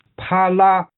啪啦/Pā lā/(Onomatopeya) sonido de cantos vítores, etc.